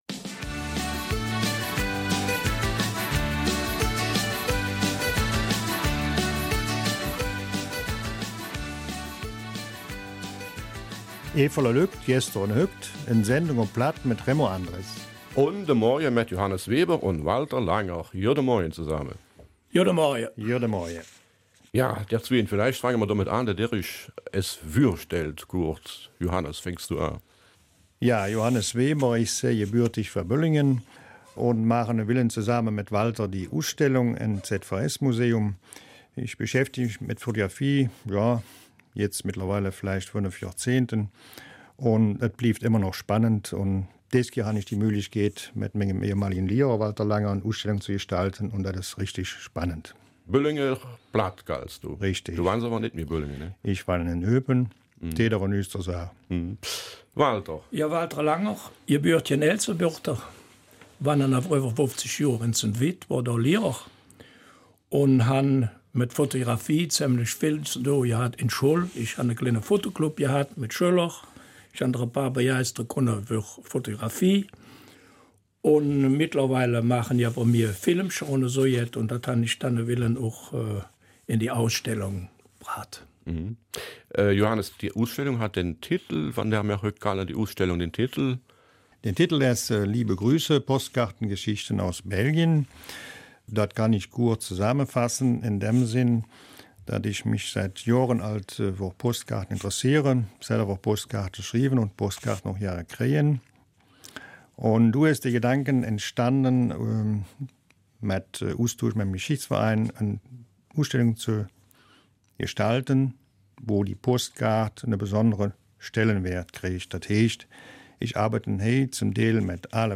Eifeler Mundart